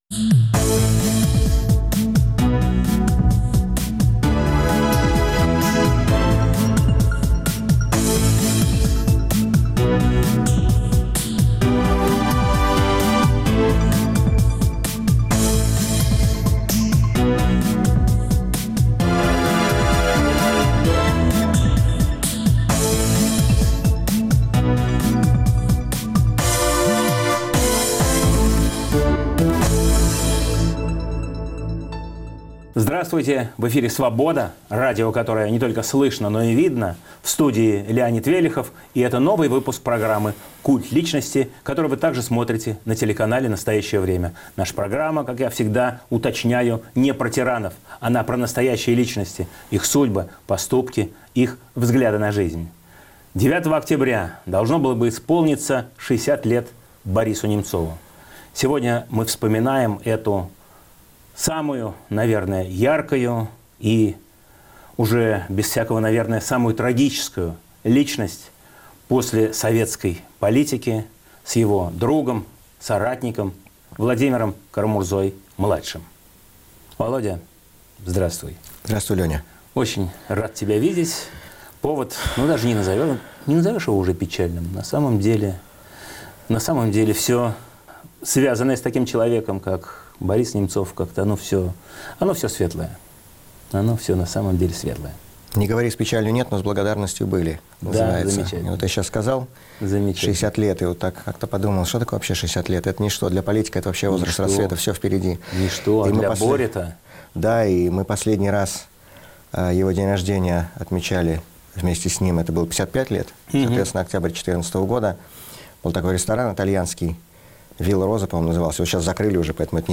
Bыпуск посвящен злодейски убитому в 2015 году назад политику, которому 9 октября этого года исполнилось бы 60 лет. В студии – его друг и сподвижник, Владимир Кара-Мурза младший.